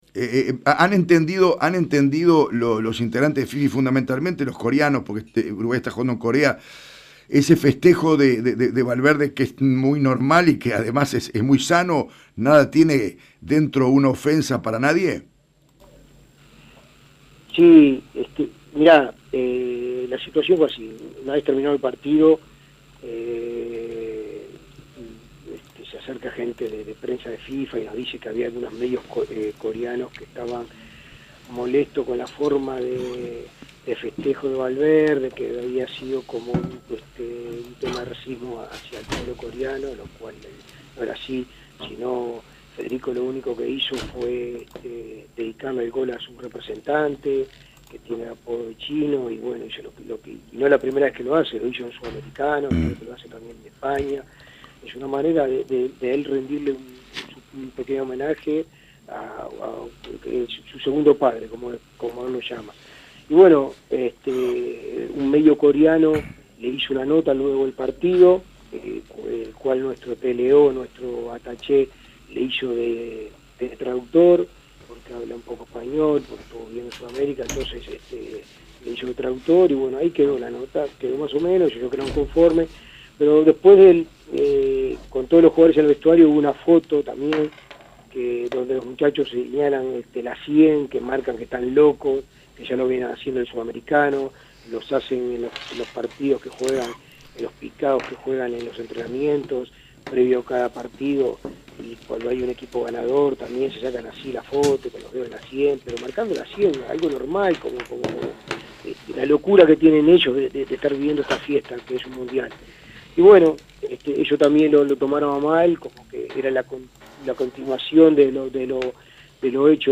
Aquí el diálogo que clarifica la situación: